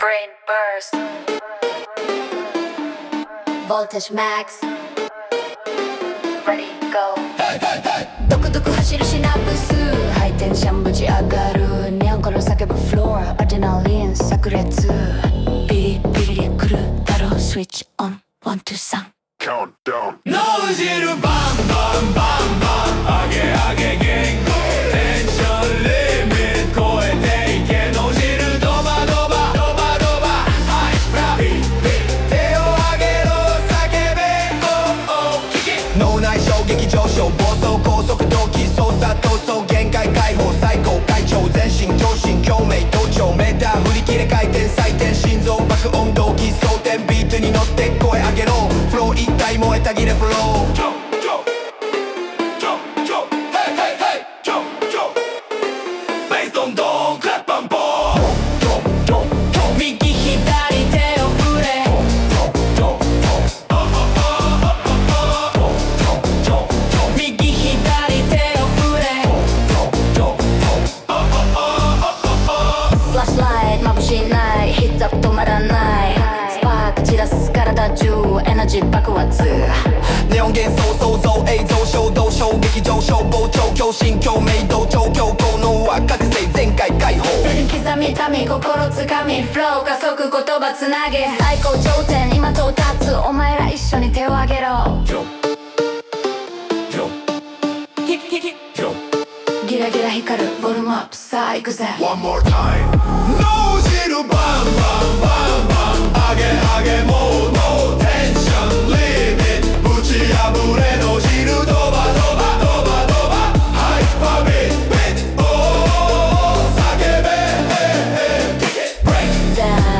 女性ボーカル
イメージ：EDM,テクノ,エレクトロ,横揺れ,男性ボーカル,女性ボーカル,パンクベース